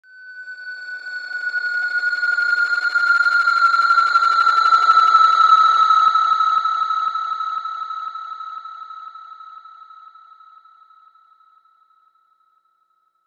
Fly By.wav